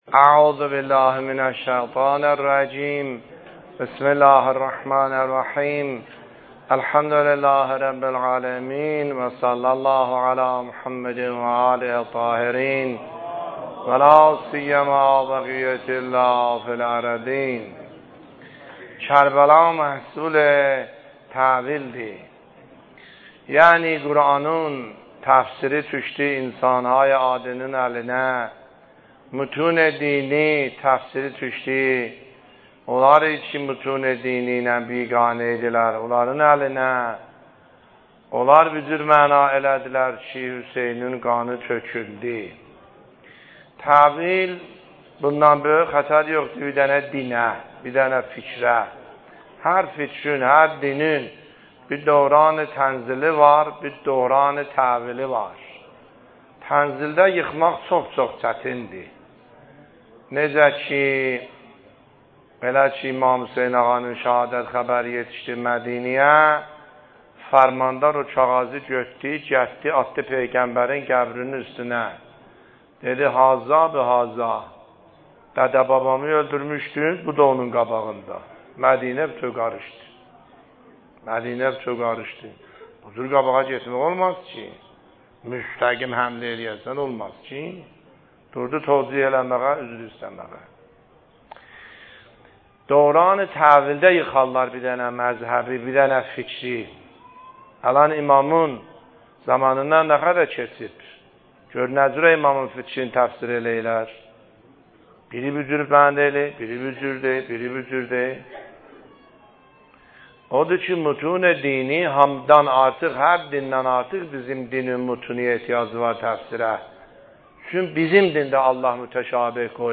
سخنرانی آیه الله سیدحسن عاملی فایل شماره۴ - دهه اول محرم ۱۳۹۷